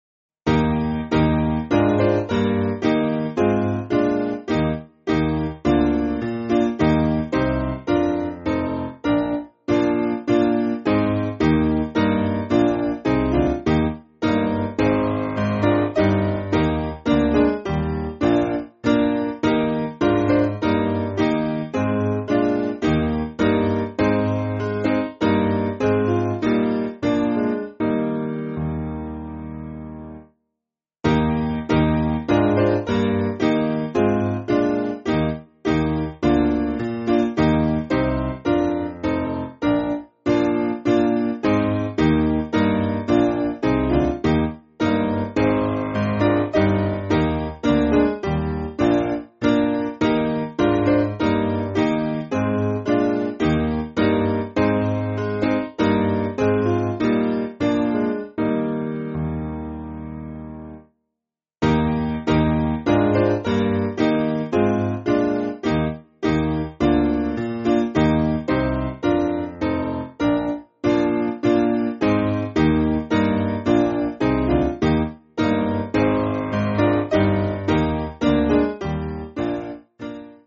Simple Piano
4/Eb